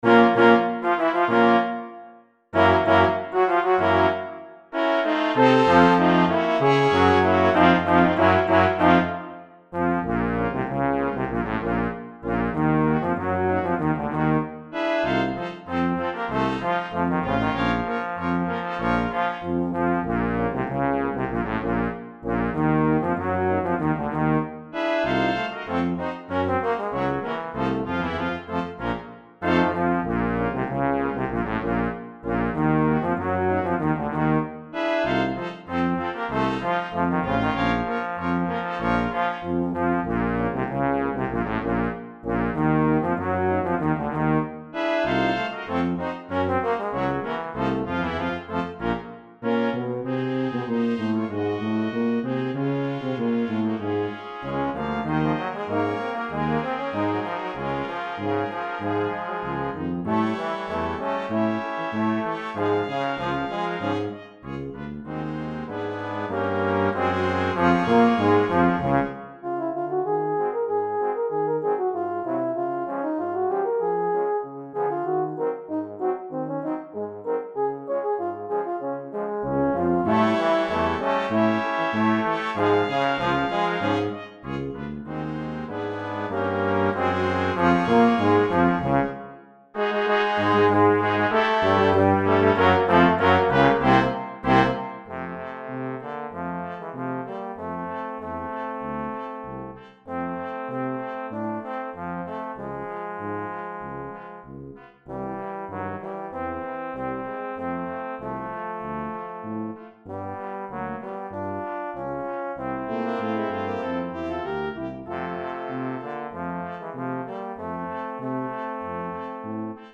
3 Trumpets
2 Horns in F
2 Trombones
Euphonium
Tuba
for Brass Nonet